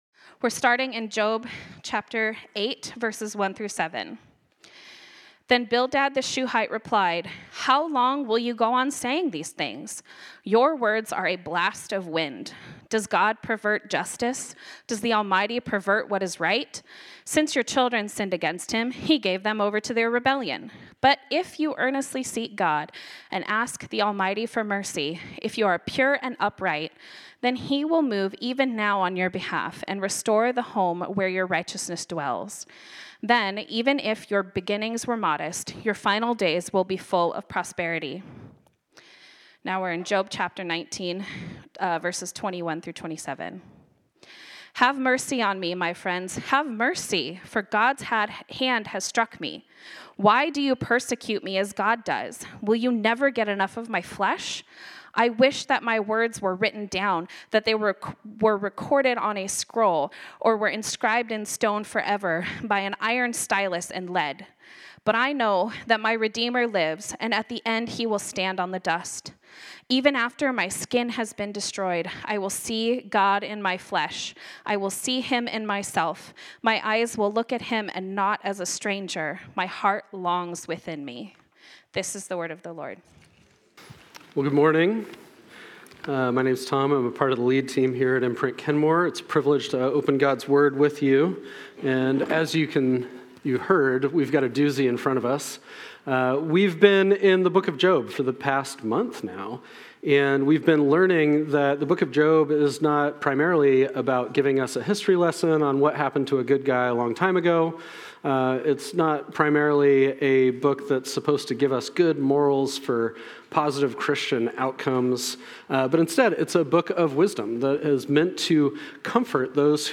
This sermon was originally preached on Sunday, February 1, 2026.